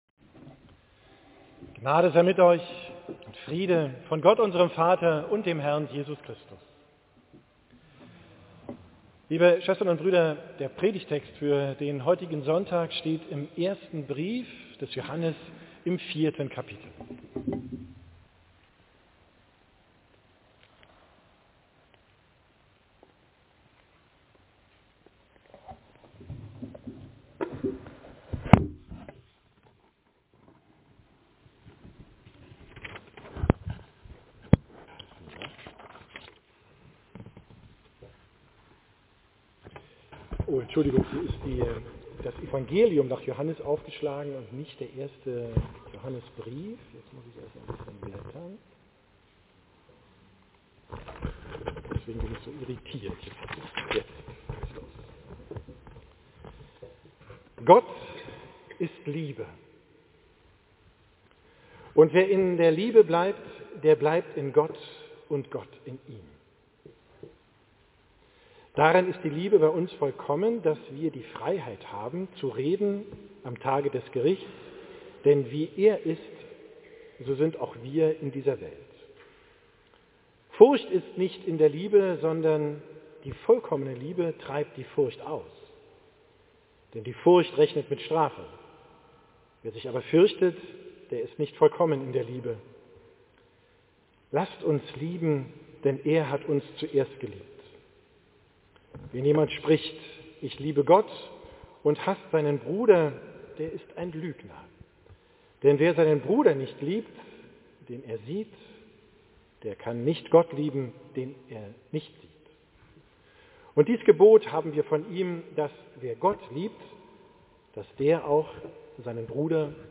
Predigt vom 1.